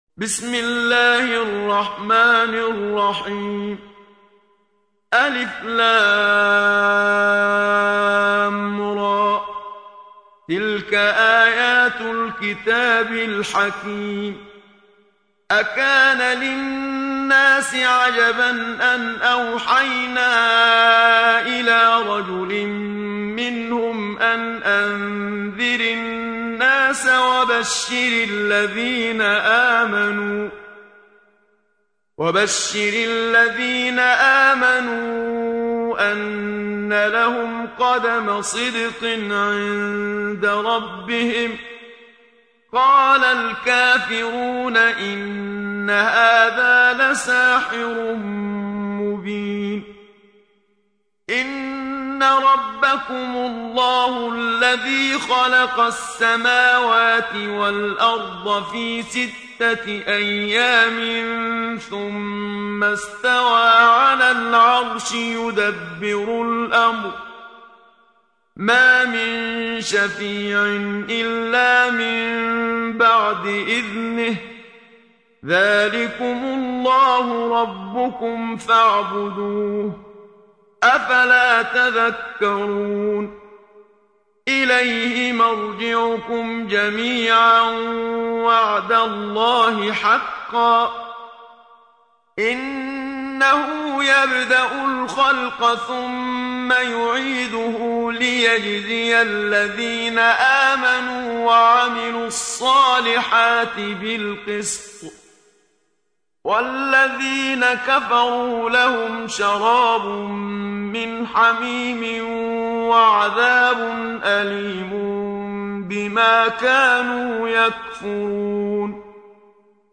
سورة يونس | القارئ محمد صديق المنشاوي